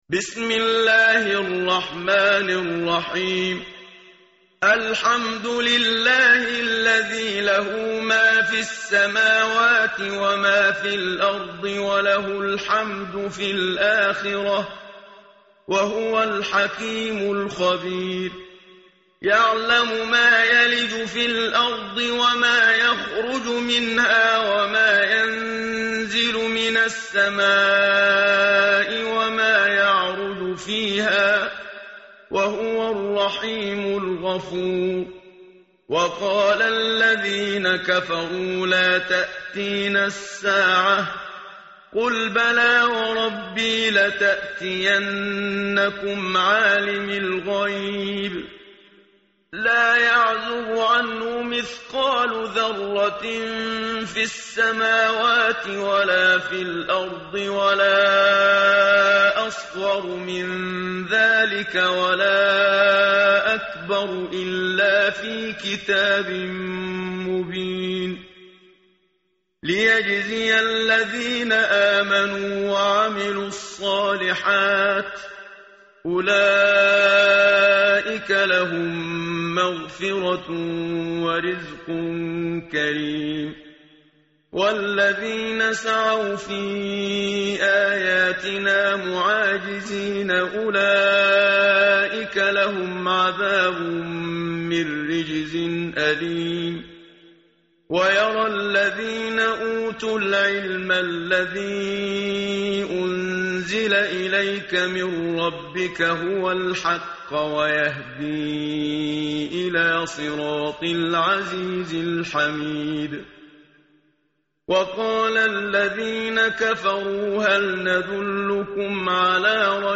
tartil_menshavi_page_428.mp3